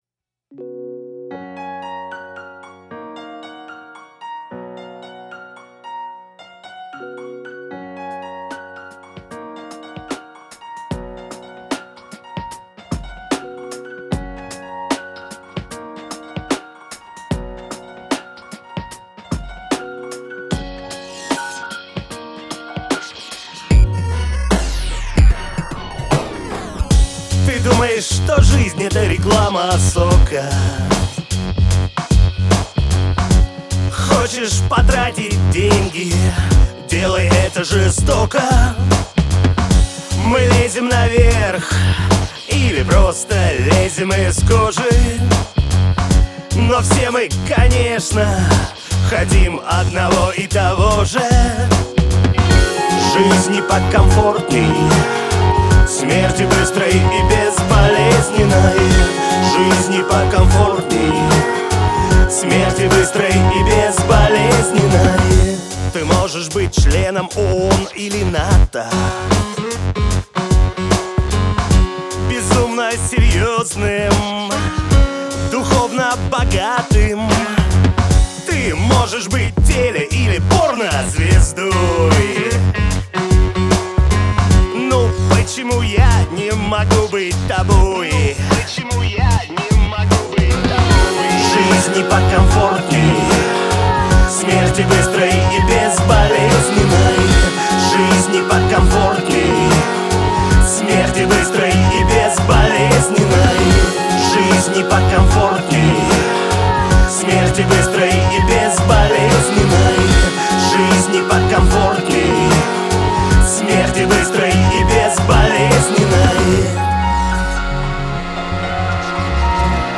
Gospel version